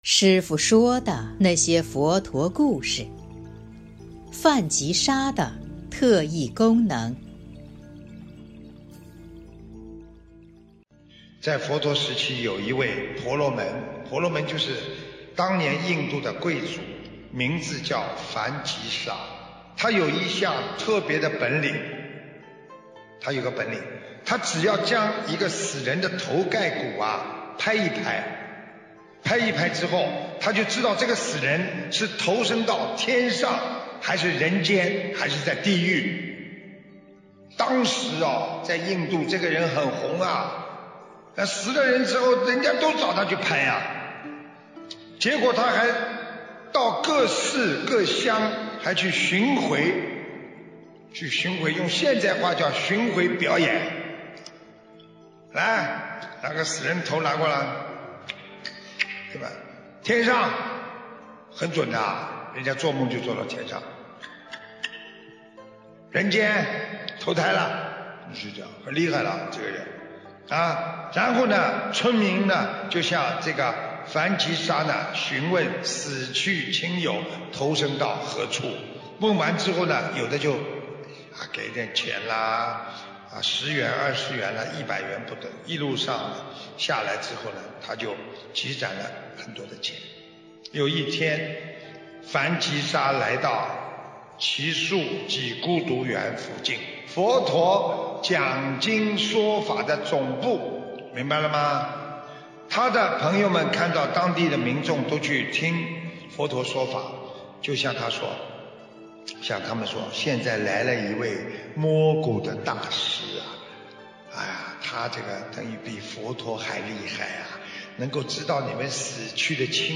音频：《梵吉沙的特异功能》师父说的那些佛陀故事！（后附师父解说）！2020年01月22日【师父原声音】